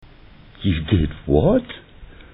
A conversation between two students.
Taking the context of the conversation and the way the man spoke, you can understand that he is not only surprised, but admires the woman for being so bold.